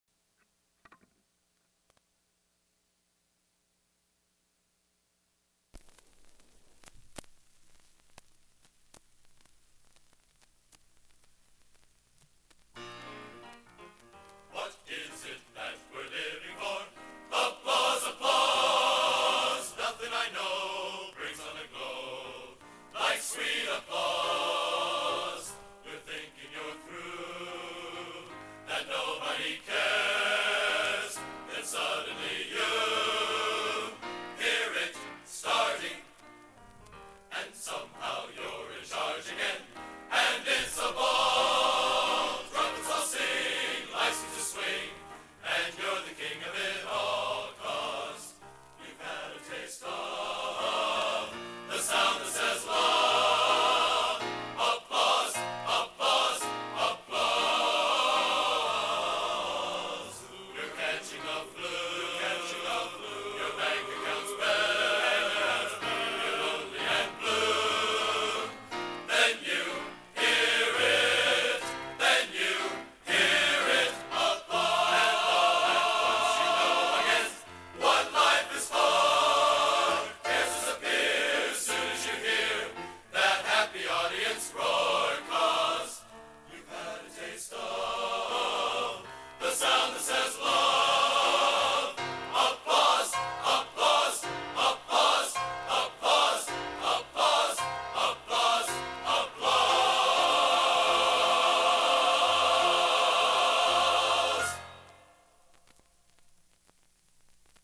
Location: West Lafayette, Indiana
Genre: | Type: Studio Recording